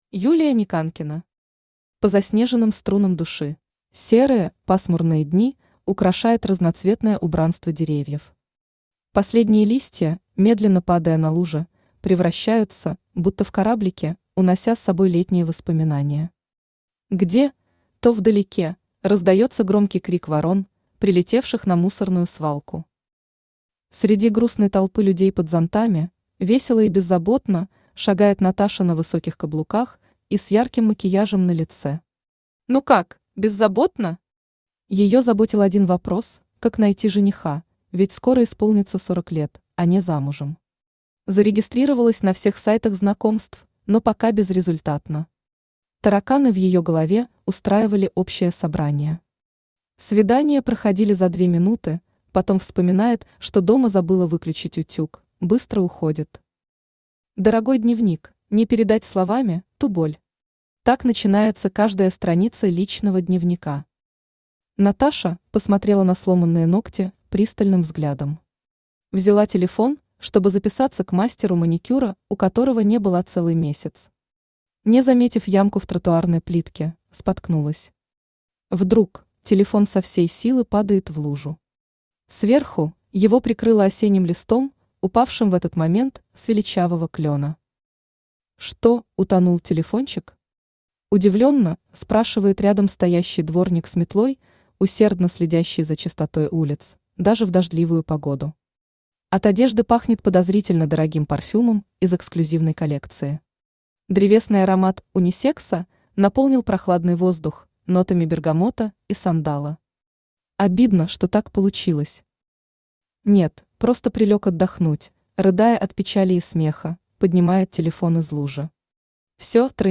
Аудиокнига По заснеженным струнам души | Библиотека аудиокниг
Aудиокнига По заснеженным струнам души Автор Юлия Николаевна Никанкина Читает аудиокнигу Авточтец ЛитРес.